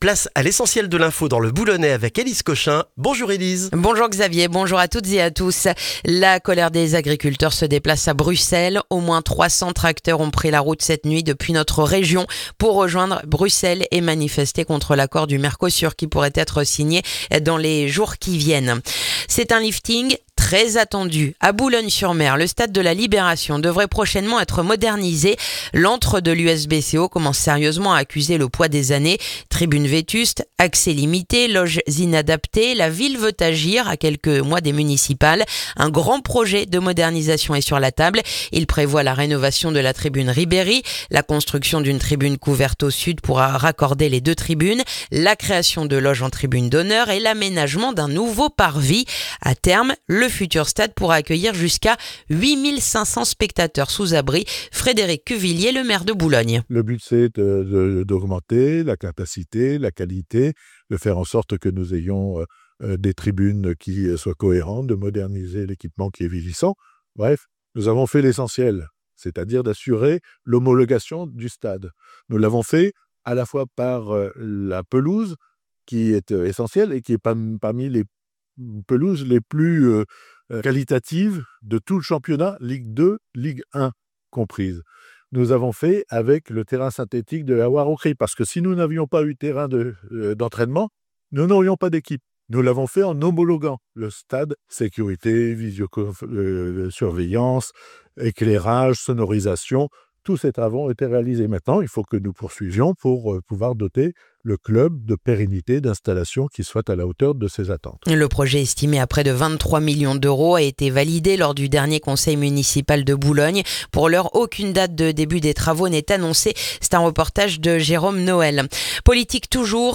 Le journal du jeudi 18 décembre dans le boulonnais